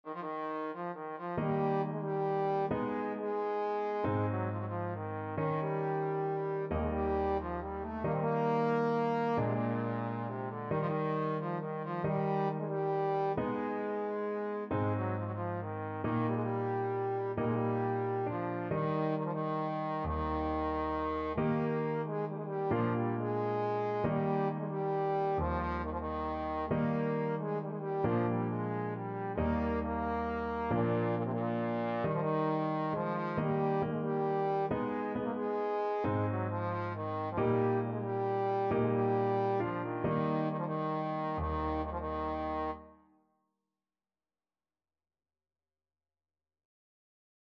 World Trad. Joc In Patru (Romanian Folk Song) Trombone version
Traditional Music of unknown author.
Eb major (Sounding Pitch) (View more Eb major Music for Trombone )
Bb3-Bb4
One in a bar .=45
3/4 (View more 3/4 Music)
romania_joc_in_patru_TBNE.mp3